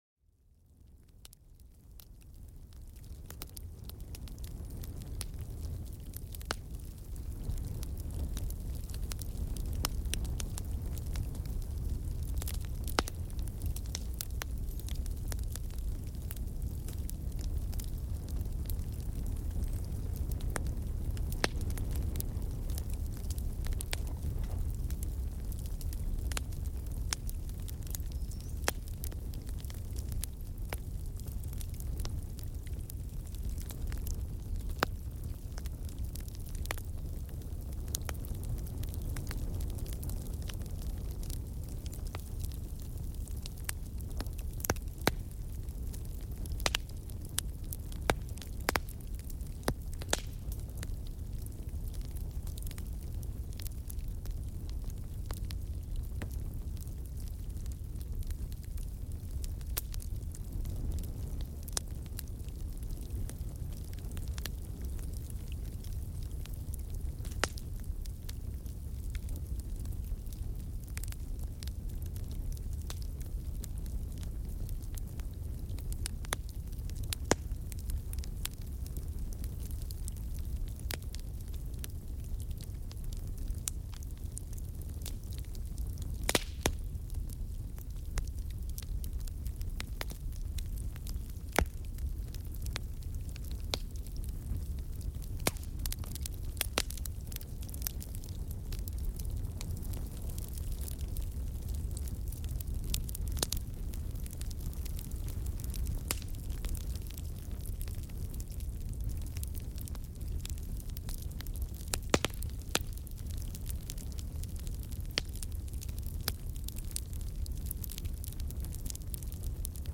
Relájate con el relajante sonido del fuego para un sueño profundo y reparador
Déjate llevar por los suaves crepitares del fuego para calmar tu mente. Cada crepitar crea una atmósfera cálida, perfecta para relajarse.